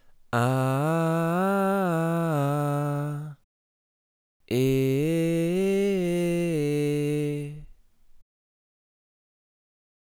Beim genaueren hinhören ist mir dann bei beiden Vocals (weibliche & männliche Stimme) eine recht unangenehme (Resonanz ?) aufgefallen...
Und an diesen sehr ekligen Resonanzen ändert sich rein garnichts!
Hier nochmal ein Audiobeispiel ( mit dem Behring C-1 und einem neuen Kabel: Anhänge Behringer C-1.wav 2,5 MB · Aufrufe: 277